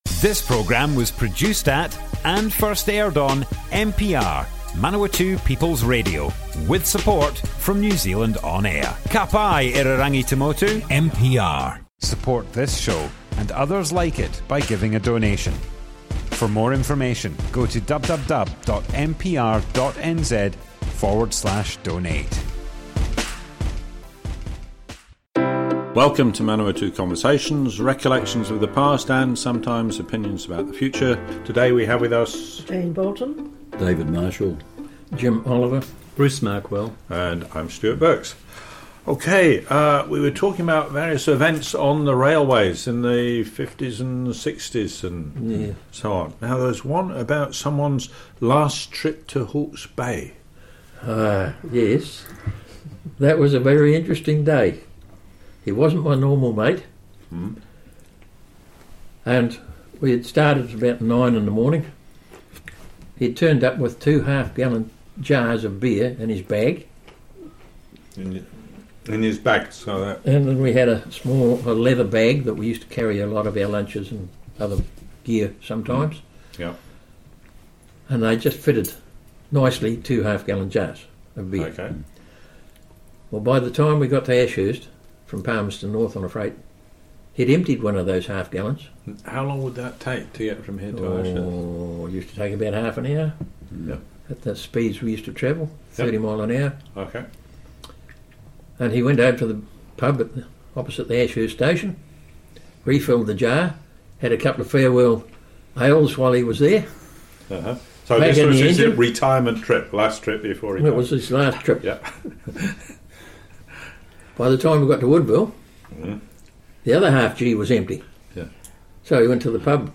Manawatu Conversations More Info → Description Broadcast on Manawatu People's Radio, 17th November 2020.
oral history